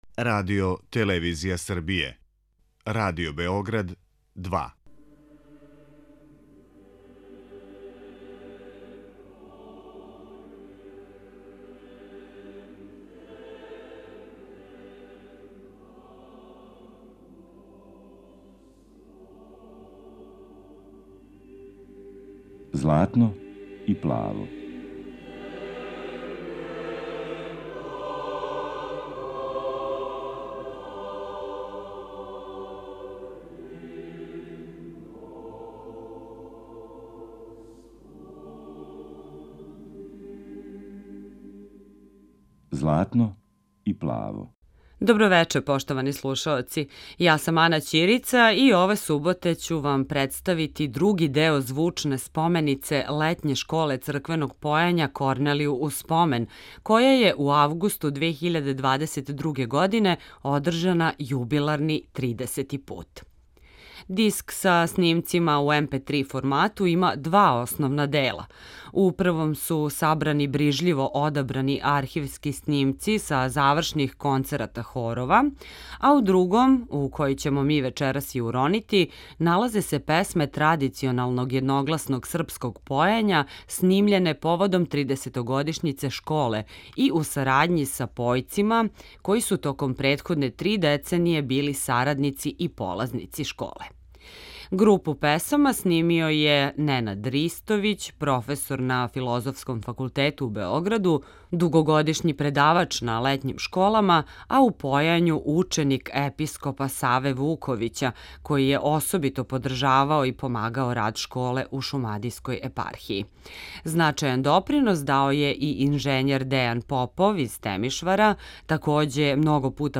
Збирком аудио снимака начињено је још једно звучно сведочанство о виталности традиционалног српског црквеног појања, нашег јединственог духовног и културног наслеђа. Ове суботе емитујемо одабране снимке који су остварени у оквиру појачке радионице Школе.